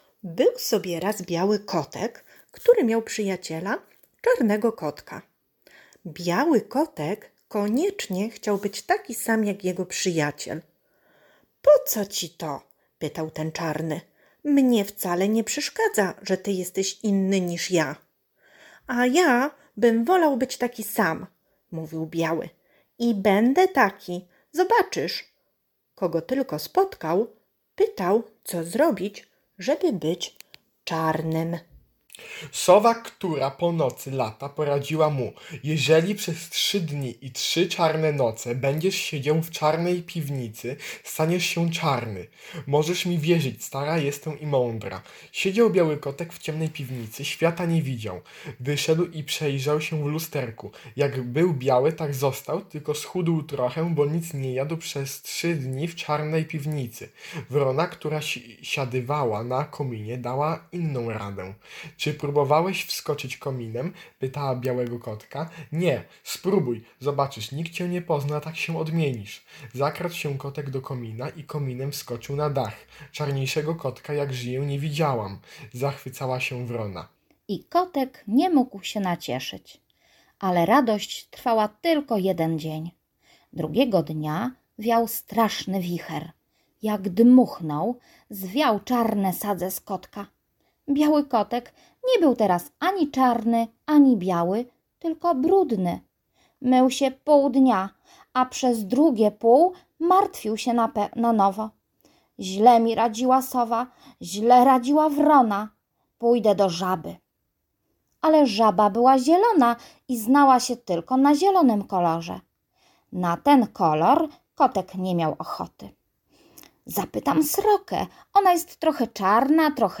Z tej okazji nagraliśmy dla Was bajkę „O kotku, który szukał czarnego mleka” H. Bechlerowej.